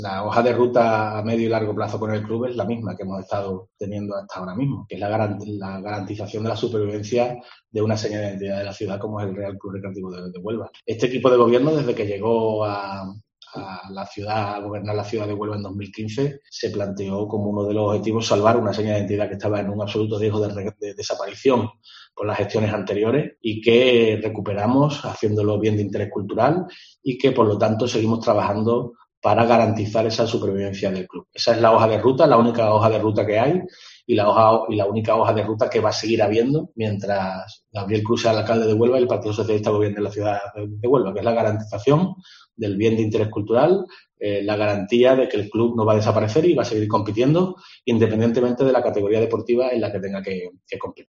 En la rueda de prensa previa al pleno de este miércoles referente al mes de febrero, Francisco Baluffo ha asegurado la supervivencia del club "compita en la categoría que compita".
Francisco Baluffo, portavoz del equipo de gobierno del Ayto Huelva